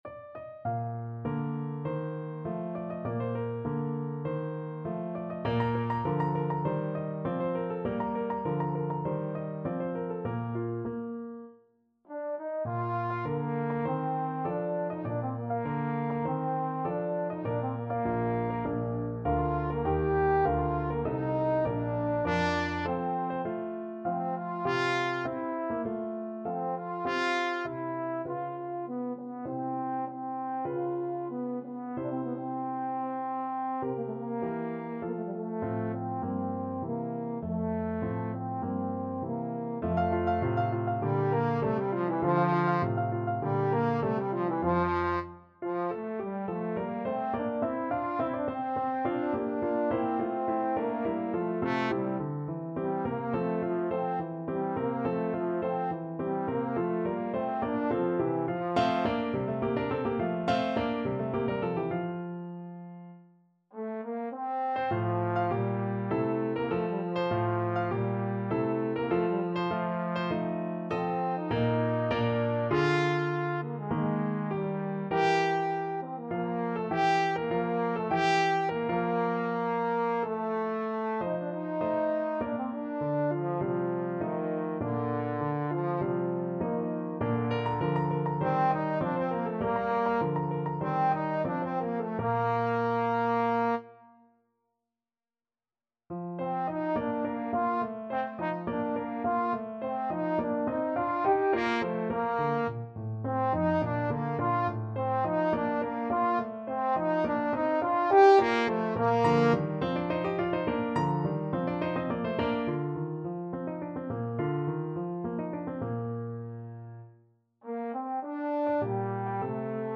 Trombone version
~ = 50 Larghetto
2/4 (View more 2/4 Music)
Classical (View more Classical Trombone Music)